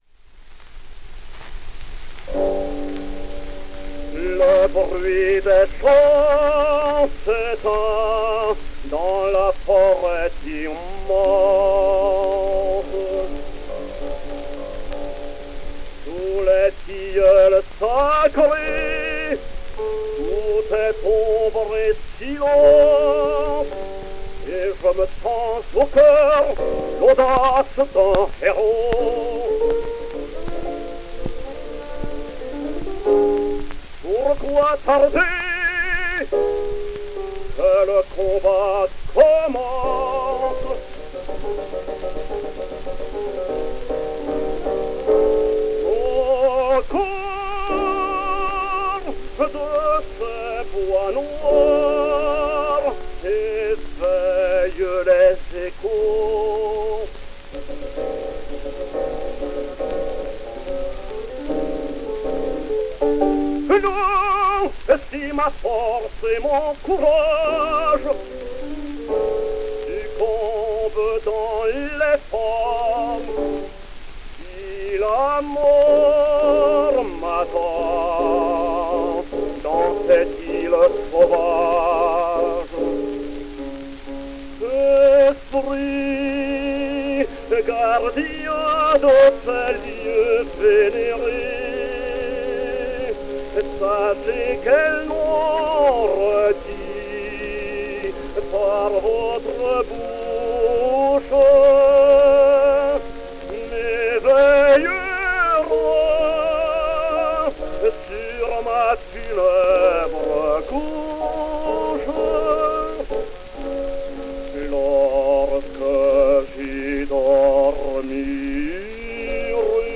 Beka, Paris, November 1906